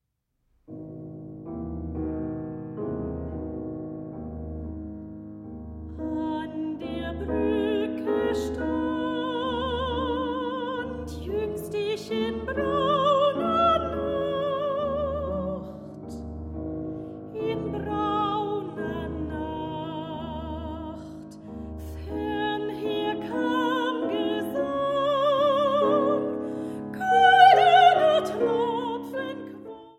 Sopran
Klavier